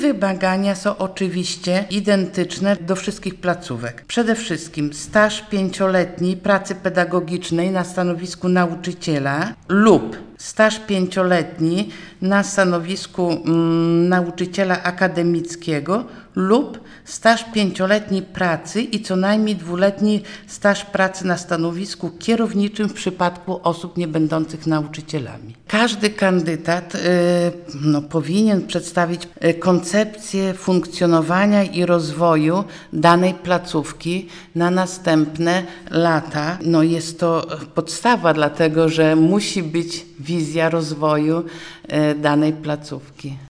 O tym jakie wymagania muszą spełniać kandydaci mówi Bożenna Puławska, Etatowy Członek Zarządu Powiatu Ełckiego.